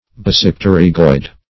Meaning of basipterygoid. basipterygoid synonyms, pronunciation, spelling and more from Free Dictionary.